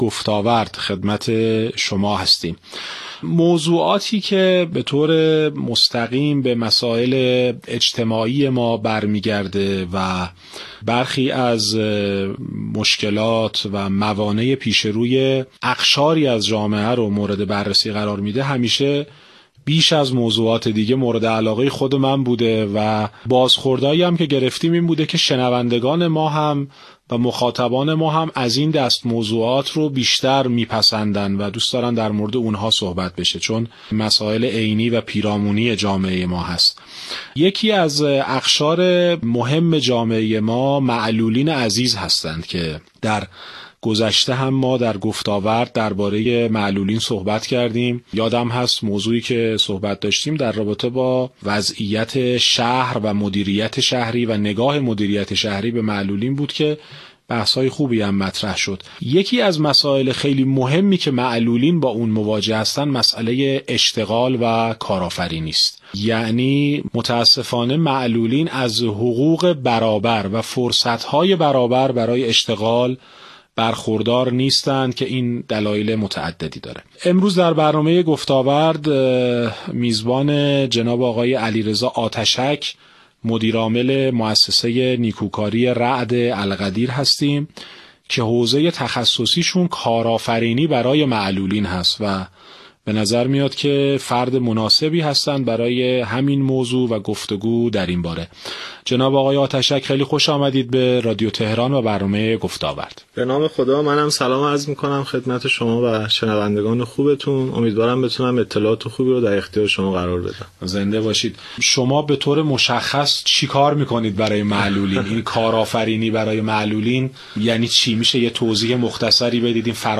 برنامه رادیویی مصاحبه و گفتگو